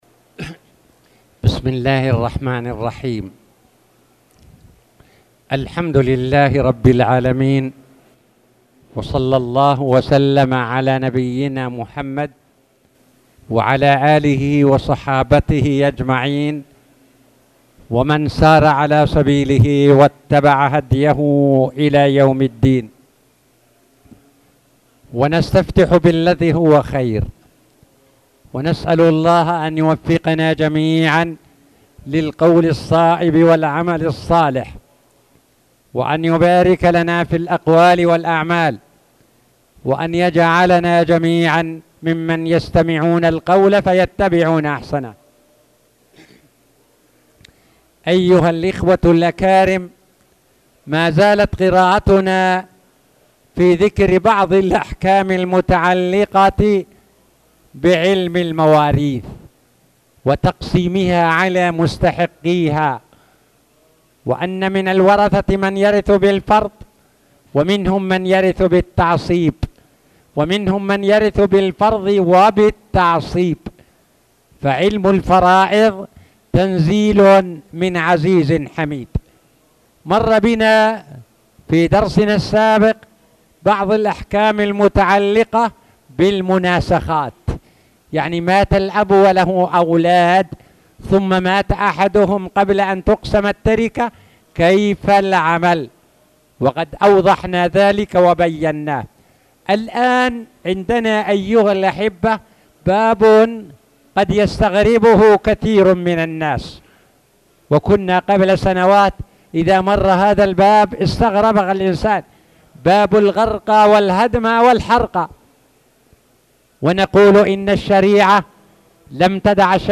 تاريخ النشر ٤ ذو القعدة ١٤٣٧ هـ المكان: المسجد الحرام الشيخ